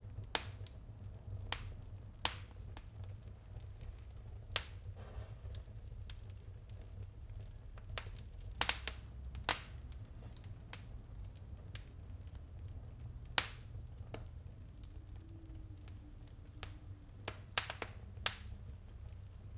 fireplace.wav